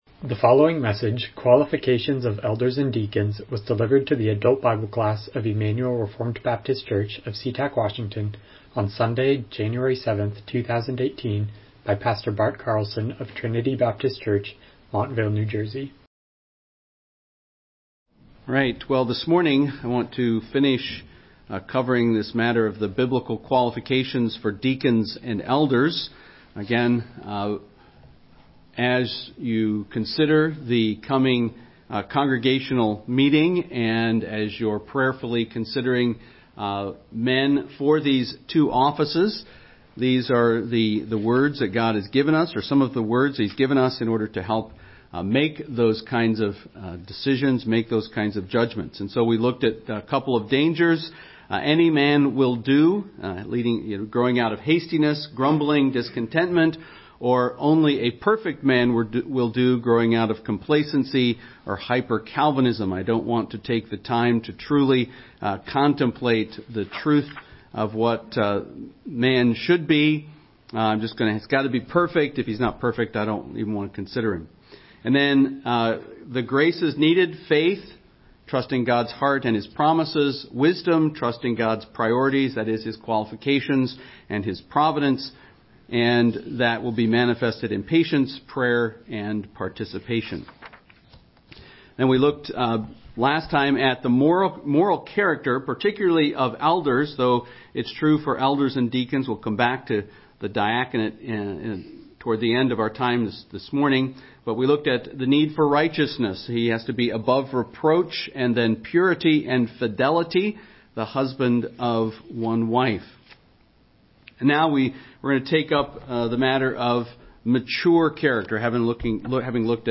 Miscellaneous Service Type: Sunday School « Holiness Requires Purity